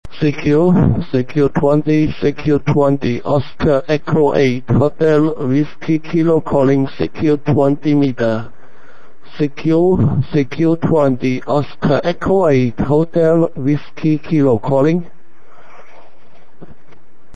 Here are some audio samples of the stations i have worked on Digital voice..
Received with the new MELP Codec in WinDRM. (Nov 2005)